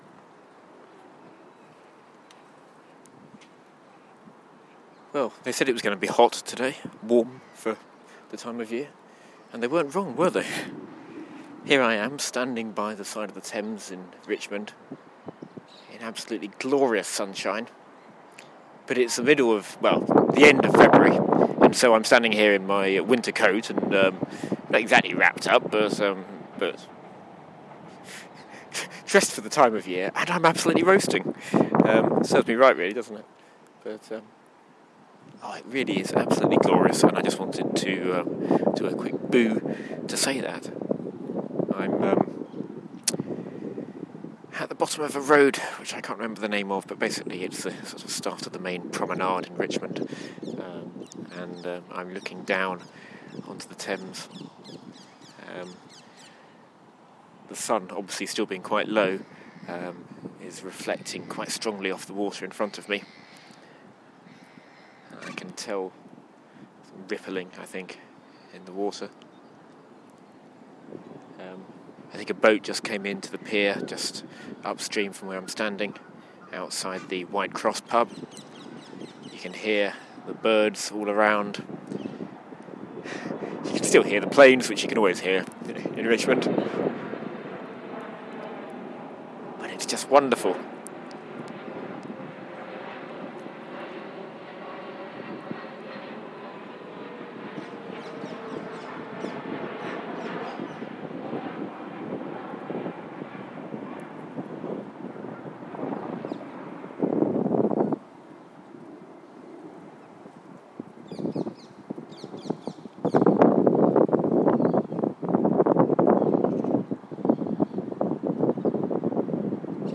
Warm day by the Thames - in February